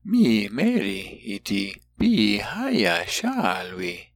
When paragraphs of Láadan are given, sound files will be provided for the entire paragraph as well as each sentence.